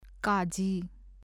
ကကြီး [kâ-jí ]子音字「က」の名前。